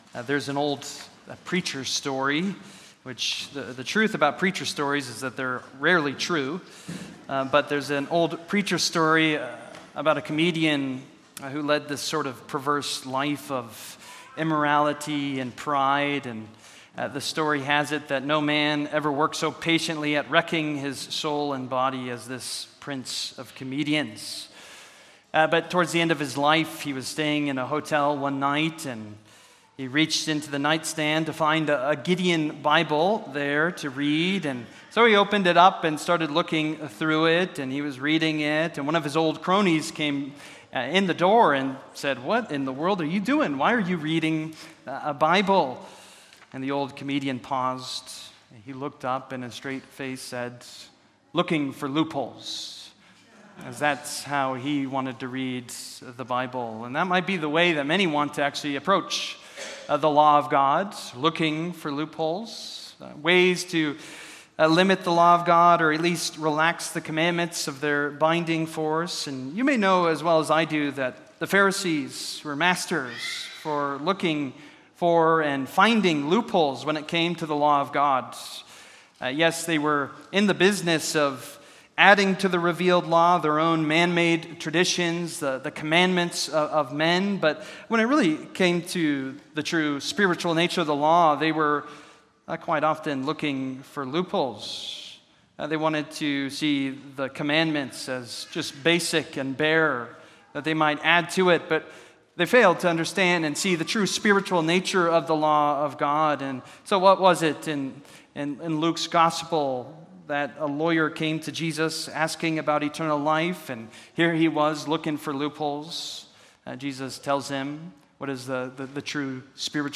Service: Sunday Morning